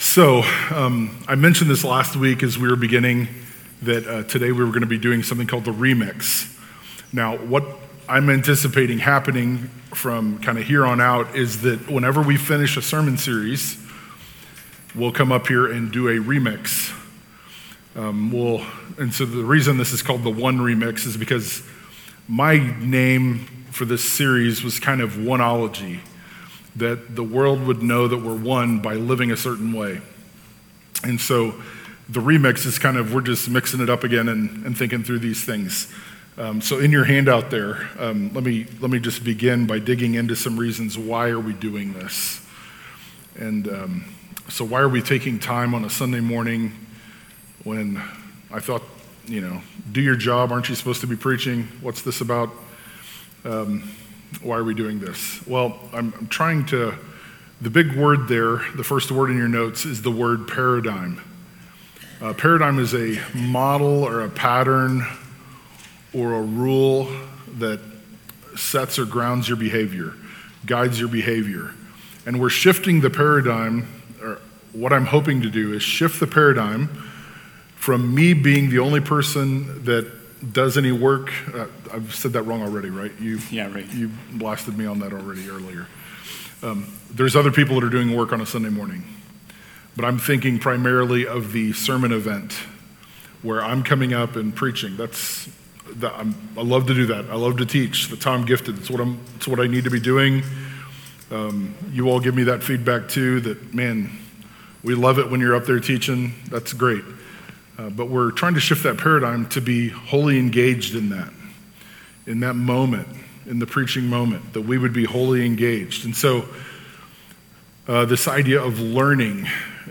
2025 One-ology John 13-17 In this Sunday morning talk